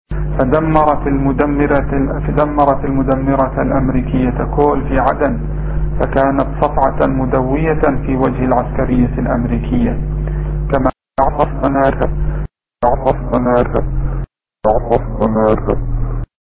さらに、2003年に公表されたスピーチの裏では、
(mp3) というリバース・スピーチも発見されたのだ。